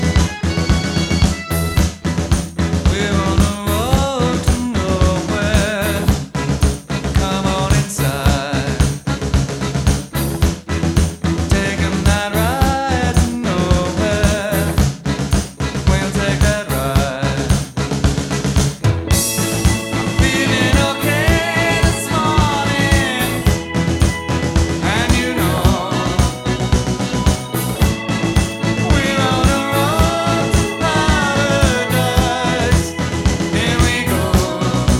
Жанр: Поп музыка / Рок / Альтернатива